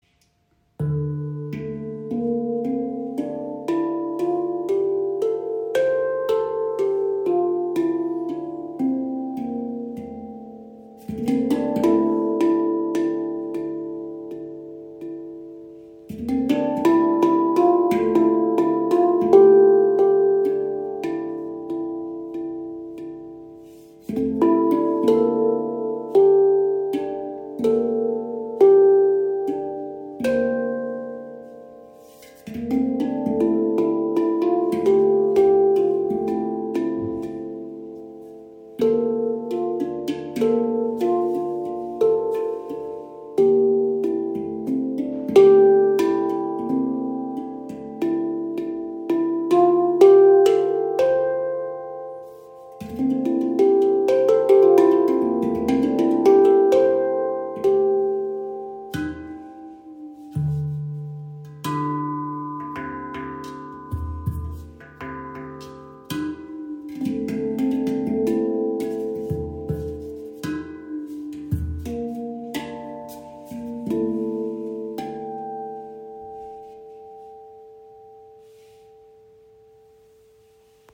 Handpand Soyuz | D Kurd
Die D Kurd Handpan klingt tief, klar und eignet sich ideal für meditative Improvisationen.